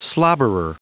Prononciation du mot slobberer en anglais (fichier audio)
Prononciation du mot : slobberer